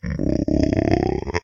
zombie-4.ogg